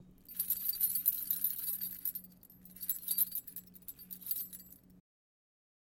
钥匙扣
描述：使用Zoom H2记录钥匙串。从长凳上捡起来，放下它，摇晃它。你可以在一些开始时听到一些roomambience，如果你想尝试学习noice并删除它，我会在最后一个样本中留下更多。
标签： 动摇 板凳 叮当 皮卡 拨浪鼓 声音FX 叮当声 钥匙圈 金属 钥匙 SFX 钥匙扣
声道立体声